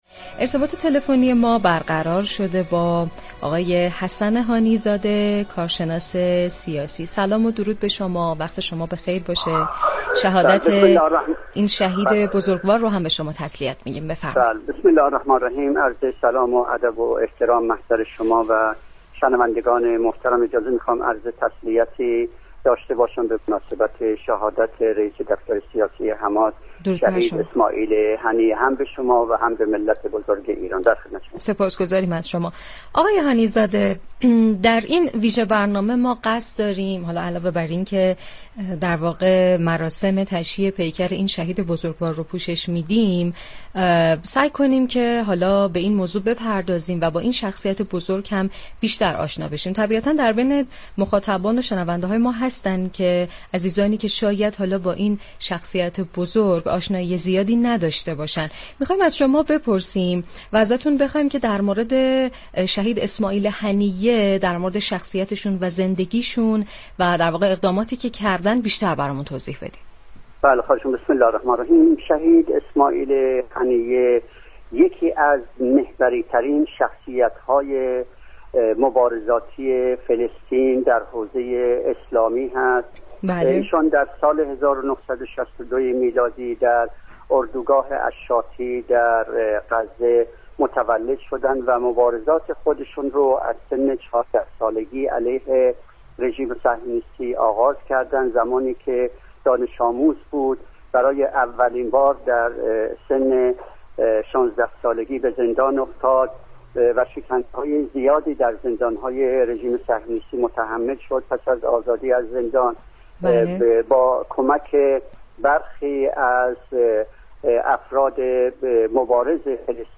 رادیو صبا در ویژه برنامه «مهمان شهید ما» با گفتگو با كارشناس سیاسی به معرفی شخصیت شهید اسماعیل هنیه پرداخت